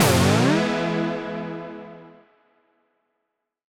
Index of /musicradar/future-rave-samples/Poly Chord Hits/Ramp Up